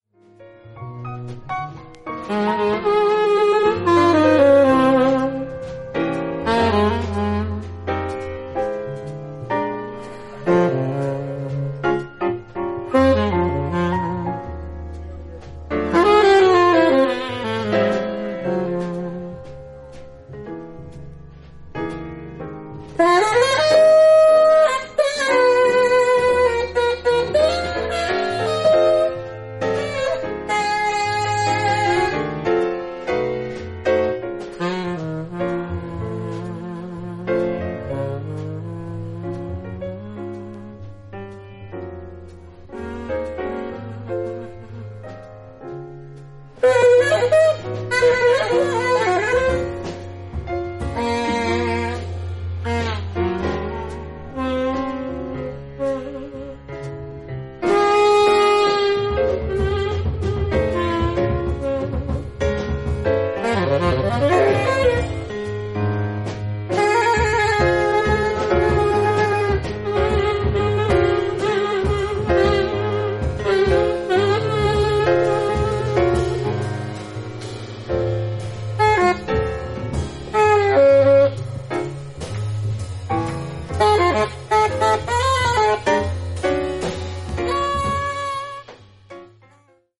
フロリダ出身のジャズ・サックス奏者